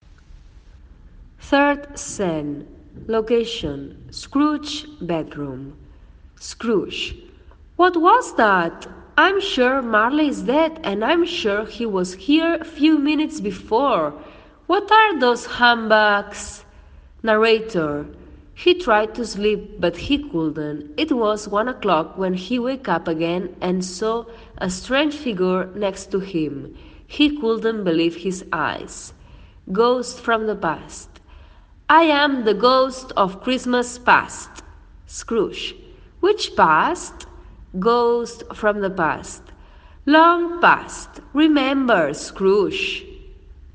There is one very slowly and the other is normal.
Normal speech: